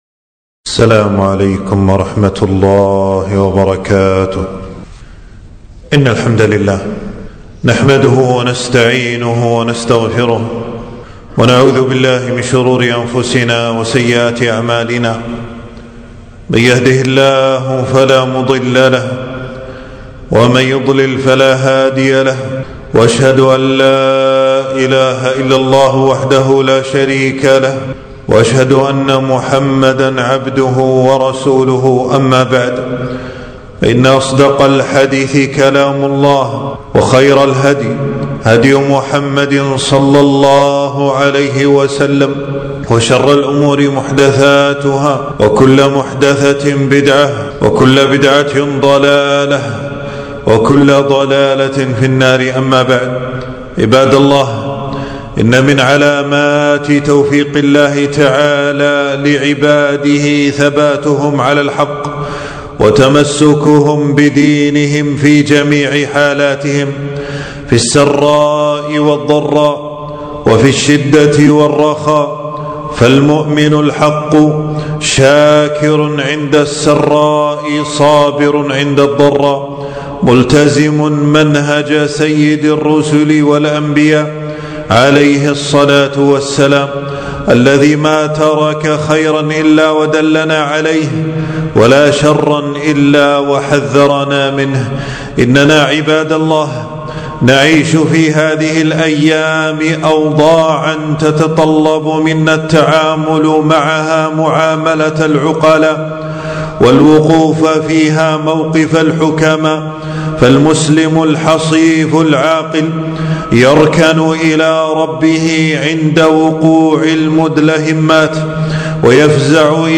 خطبة - فالله خير حافظا وهو أرحم الراحمين